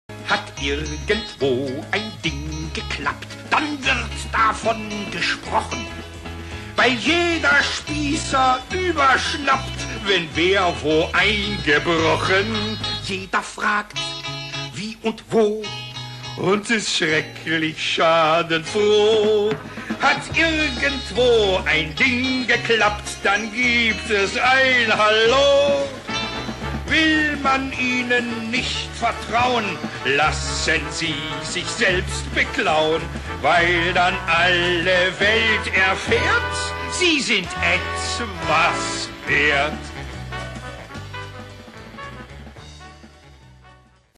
Musical für den Rundfunk (Originalhörspiel)
Besetzung: 9 Darsteller (Sprecher/Sänger)